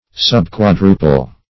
Search Result for " subquadruple" : The Collaborative International Dictionary of English v.0.48: Subquadruple \Sub*quad"ru*ple\, a. Containing one part of four; in the ratio of one to four; as, subquadruple proportion.